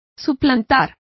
Complete with pronunciation of the translation of supersede.